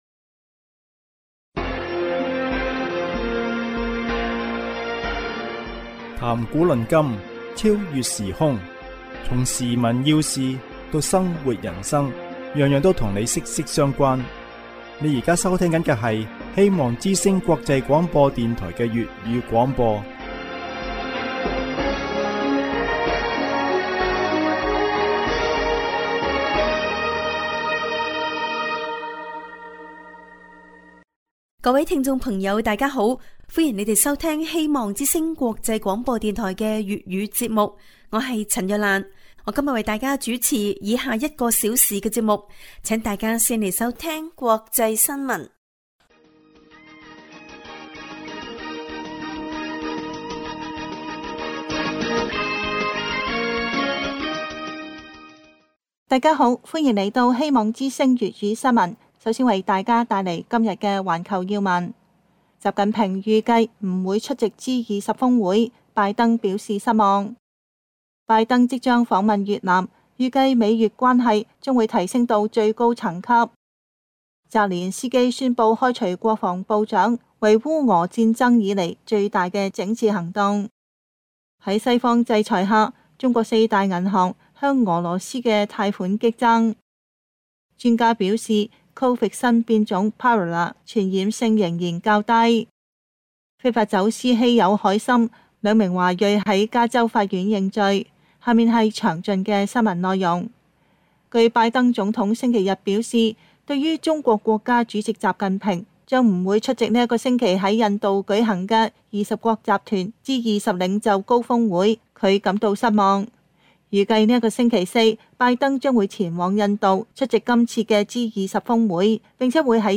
新聞節目